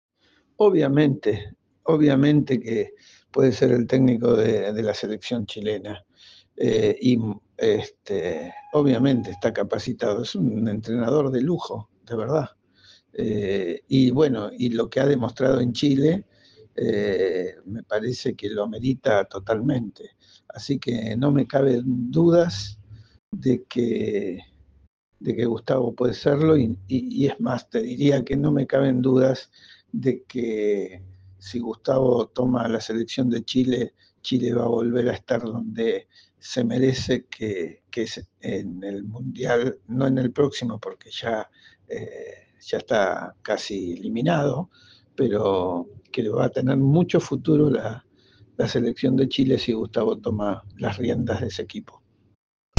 En conversación con ADN Deportes